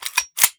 fps_project_1/45 ACP 1911 Pistol - Cocking Slide 001.wav at 94bc24be2eb335c77afa5062db760e4dc62b732e